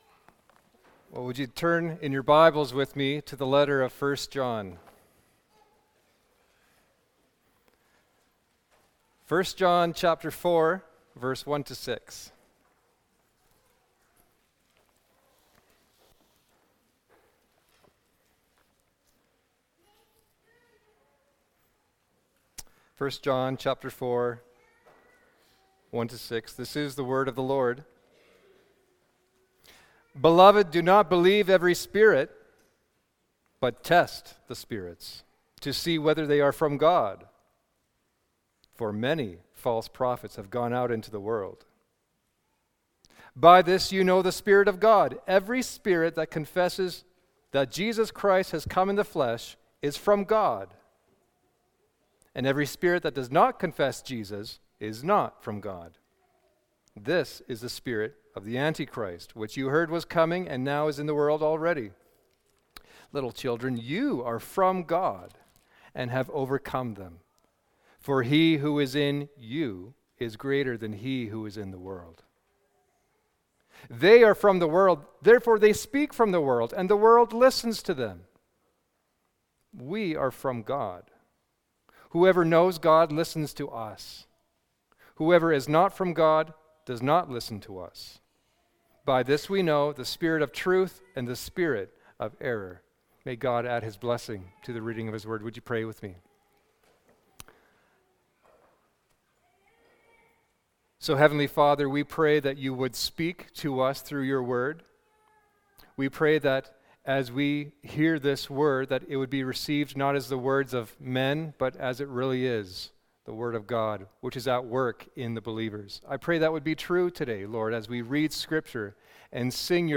Main Service